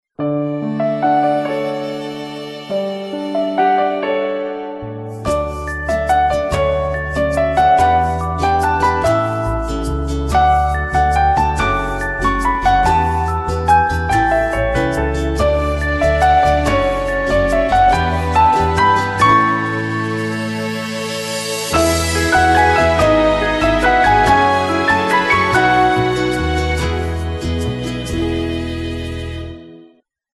• Качество: 320, Stereo
красивые
без слов
пианино
классические